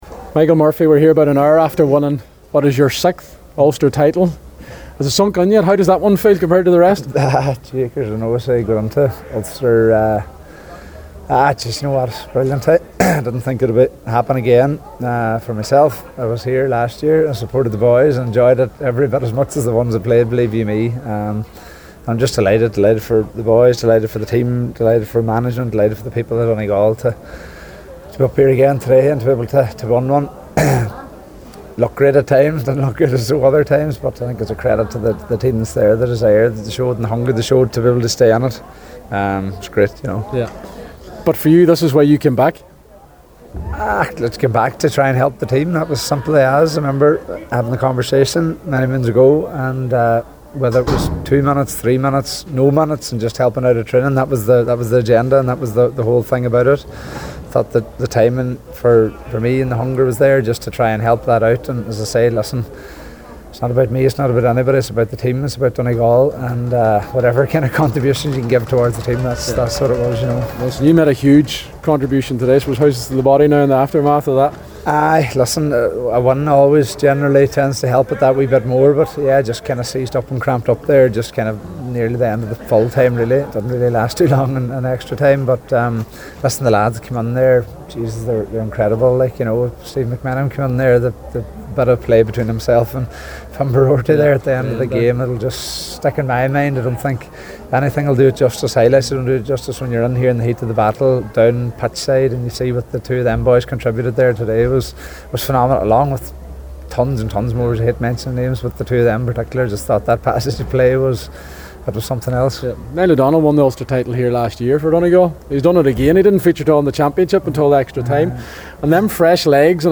spoke to Murphy afterwards as he reflected on the match and what it means to so many people in Donegal.